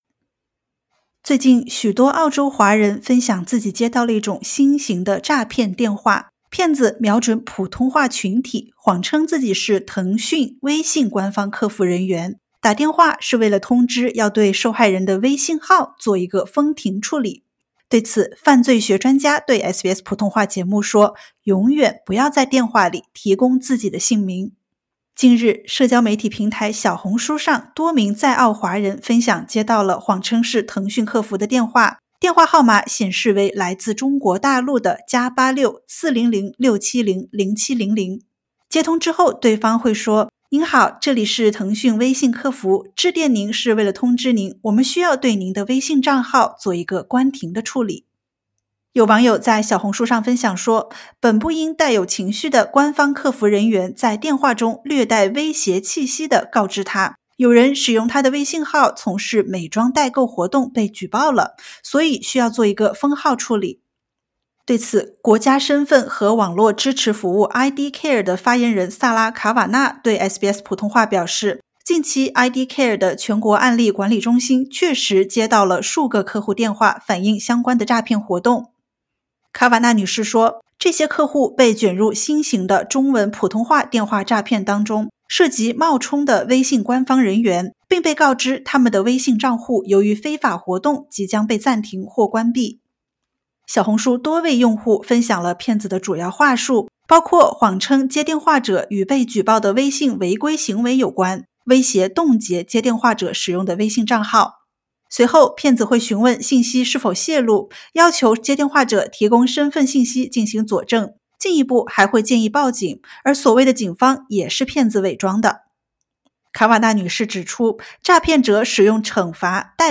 腾讯客服给我打电话说要封我的微信号？新型诈骗瞄准普通话群体，专家指出“永远不要在电话里提供自己的姓名”。（点击上图收听采访）